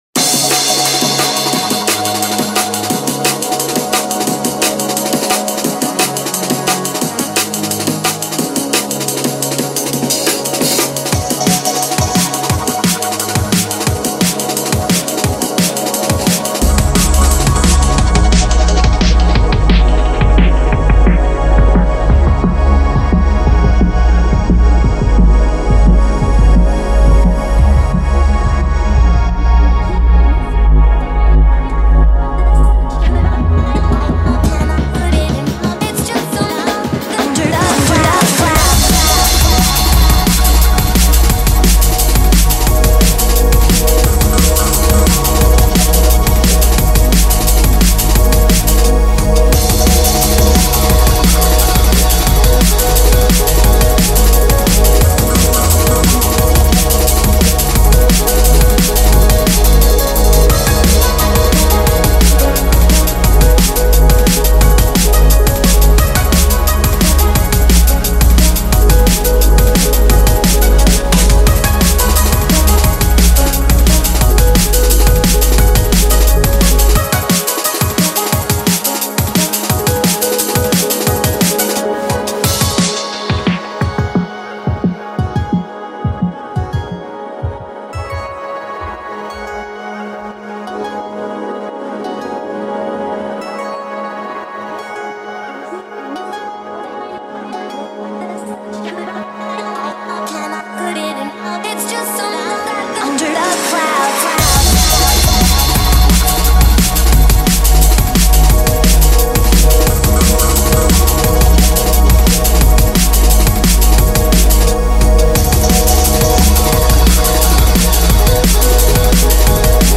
Oh man, this is way too happy...